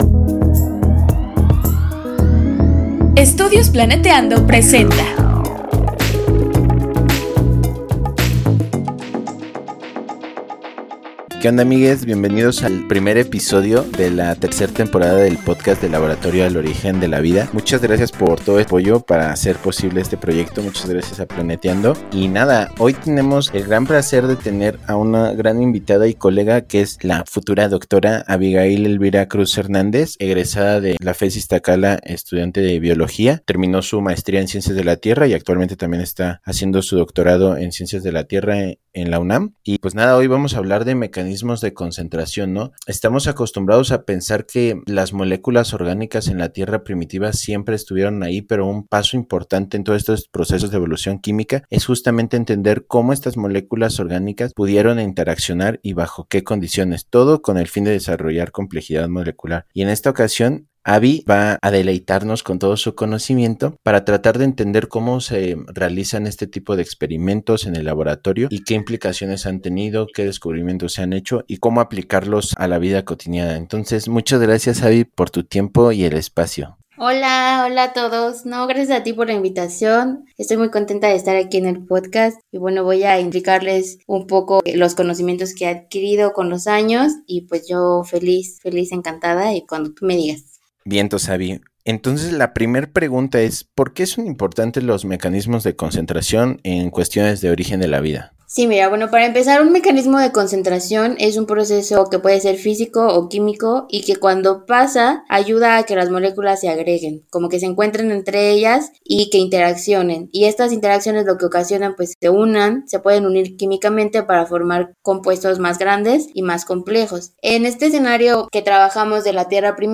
En cuestiones de origen de la vida y química prebiótica, diversos experimentos se han enfocado en entender cómo los minerales podrían favorecer la retención, descomposición, protección y transformación de diversas moléculas en diferentes ambientes primitivos. Entrevista